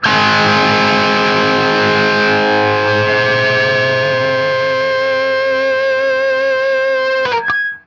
TRIAD G#  -R.wav